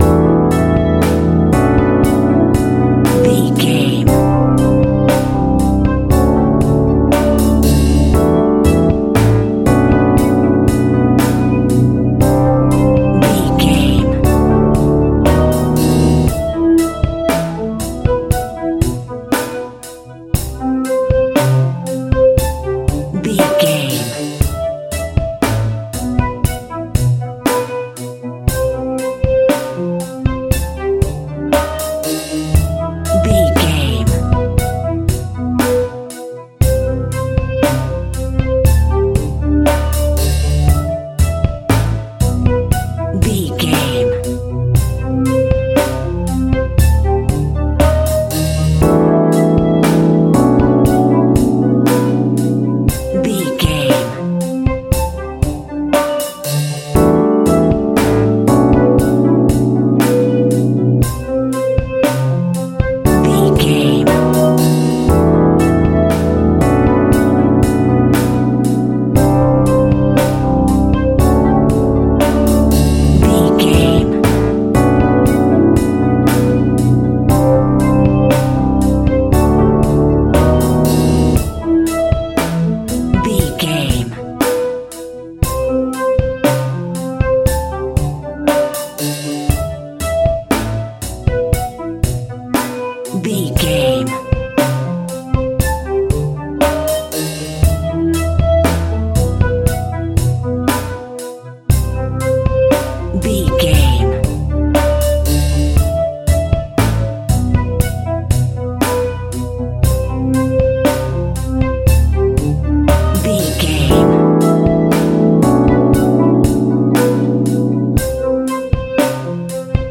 Sexy Music.
Aeolian/Minor
Funk
hip hop
electronic
drum machine
synths